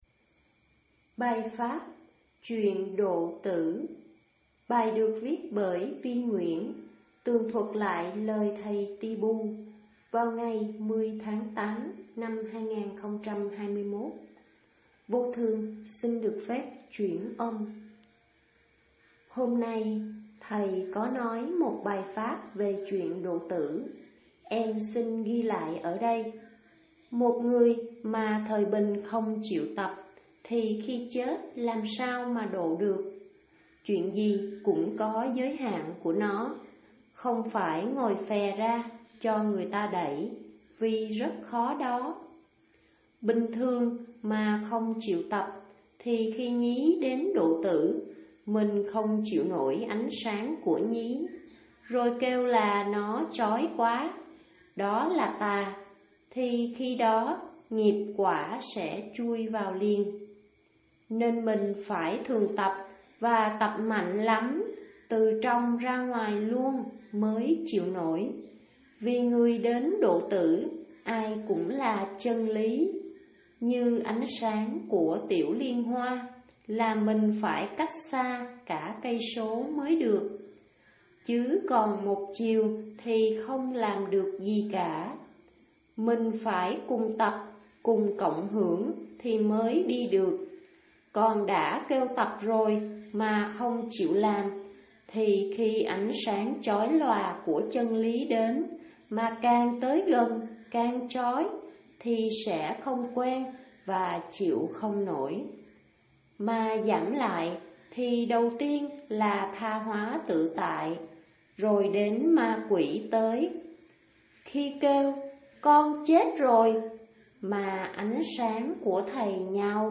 Chuyện độ tử (chuyển âm)